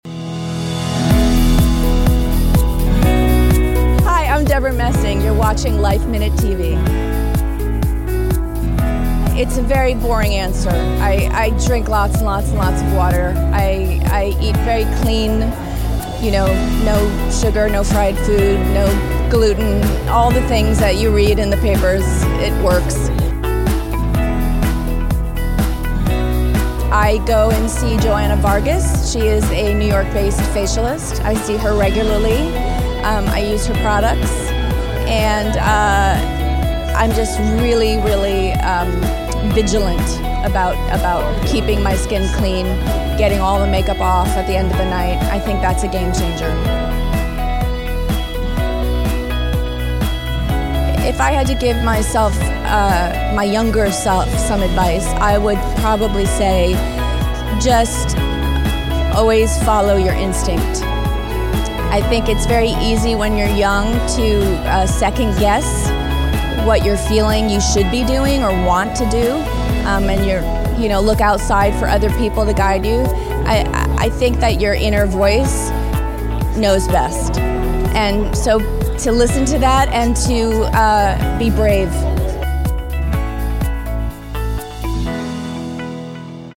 We ran into the Will and Grace star at designer Naeem Khan’s runway show at New York Fashion Week Fall 2019. Ms. Messing shared with us the "boring" way she stays healthy, her vigilant skincare routine and invaluable tips for success.